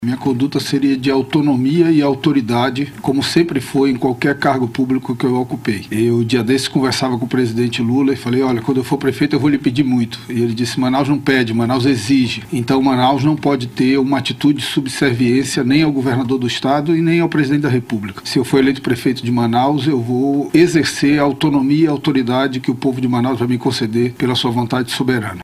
Entrevista
Em entrevista no BandNews Amazônia 1ª Edição desta segunda-feira, 16, o político respondeu sobre as críticas que o presidente Lula, que o indicou como candidato do PT em Manaus, recebeu pela maneira que conduziu o combate a crise das queimadas no Amazonas.